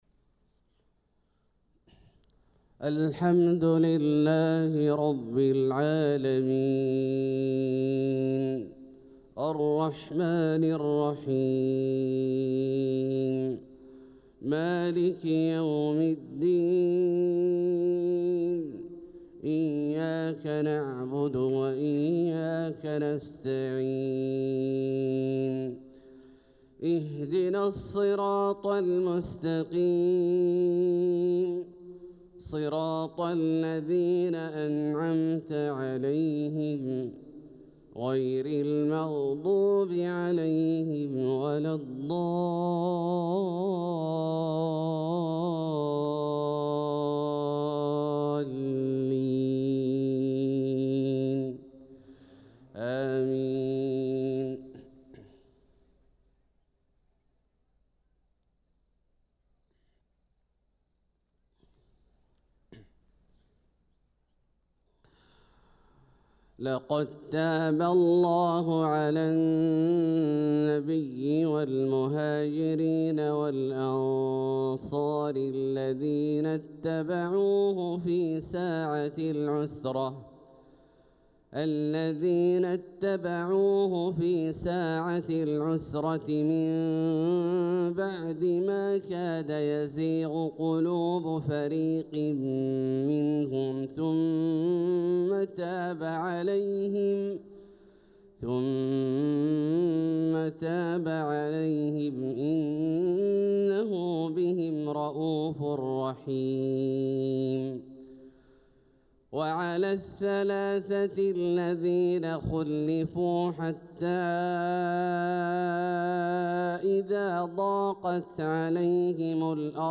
صلاة الفجر للقارئ عبدالله الجهني 12 ربيع الآخر 1446 هـ
تِلَاوَات الْحَرَمَيْن .